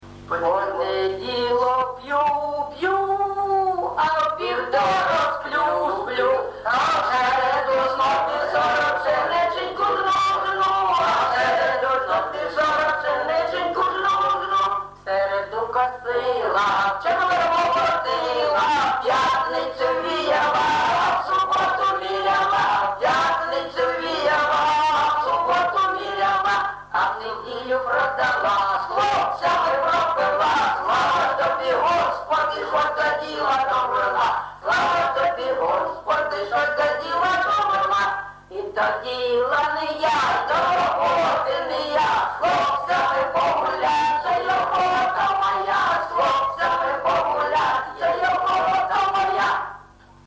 ЖанрЖартівливі, Пʼяницькі
Місце записум. Єнакієве, Горлівський район, Донецька обл., Україна, Слобожанщина